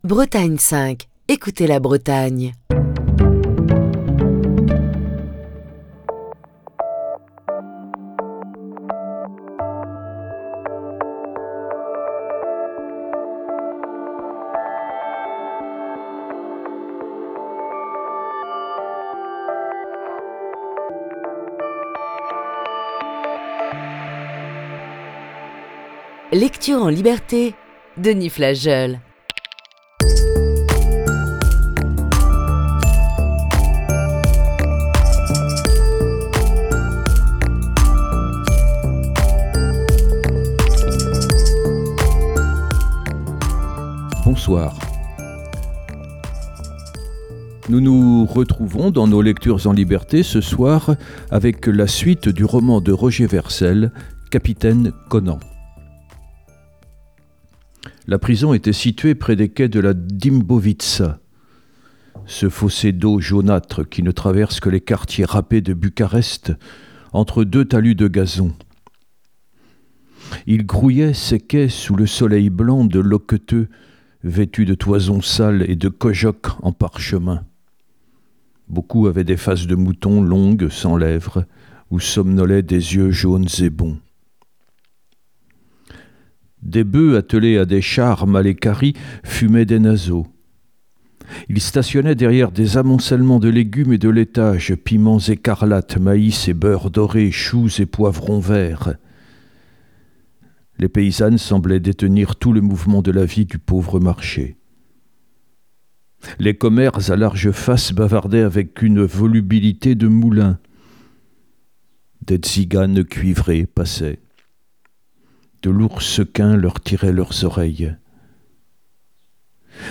Émission du 12 mai 2022.